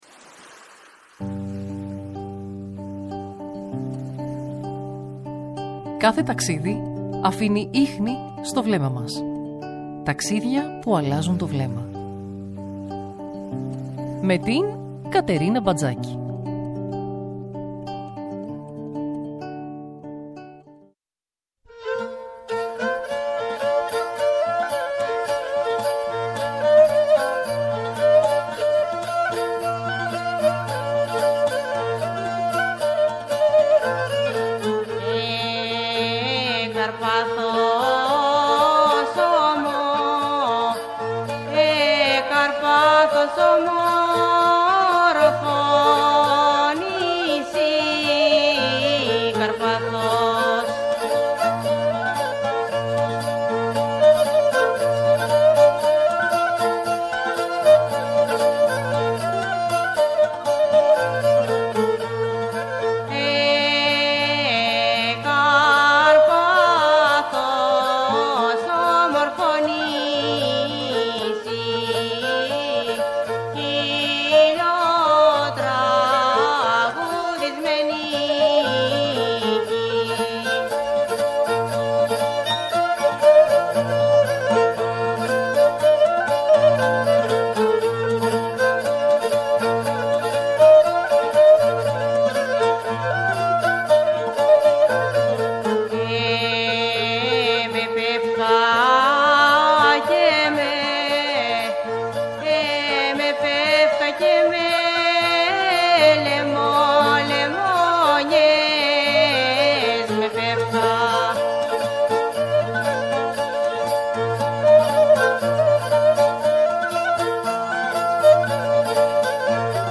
Η συζήτηση ανέδειξε τη λεπτή ισορροπία ανάμεσα στην τουριστική ανάπτυξη και την ανάγκη διαφύλαξης της αυθεντικότητας ενός τόπου που δεν επιδιώκει να αλλοιωθεί.